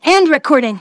synthetic-wakewords
ovos-tts-plugin-deepponies_Spike_en.wav